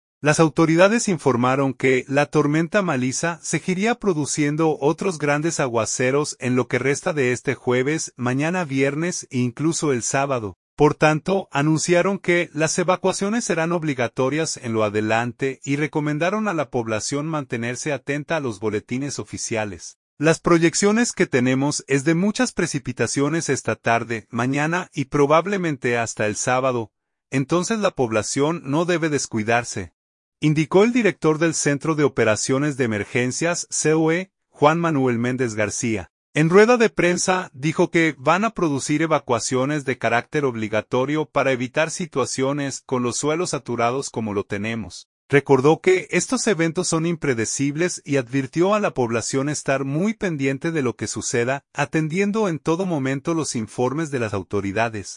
En rueda de prensa, dijo que van a “producir evacuaciones de carácter obligatorio para evitar situaciones, con los suelos saturados como lo tenemos”.